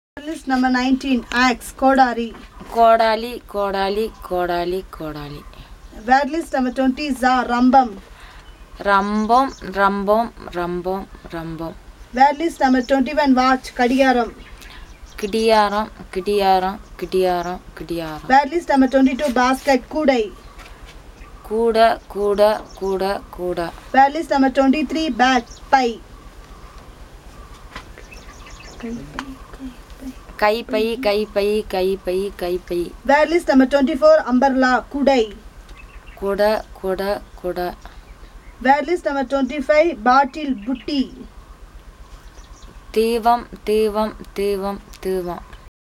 Elicitation of words about artifacts and household items (Part 3)